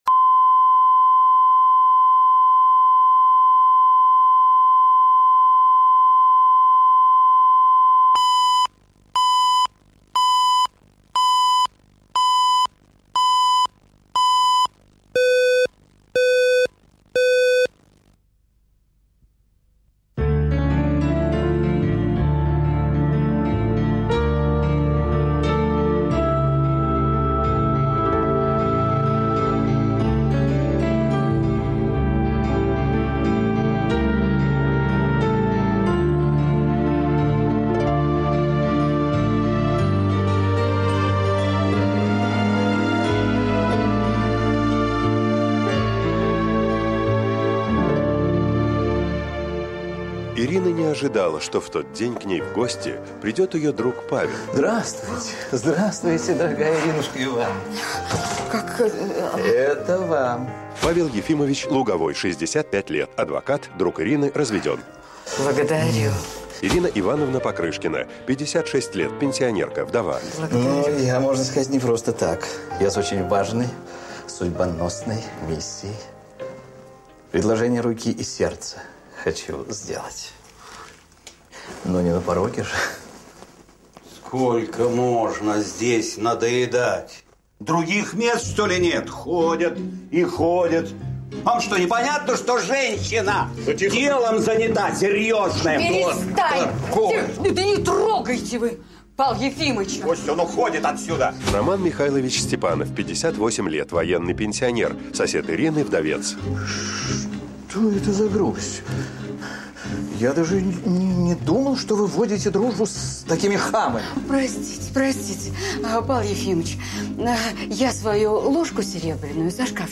Аудиокнига Лестничная клетка | Библиотека аудиокниг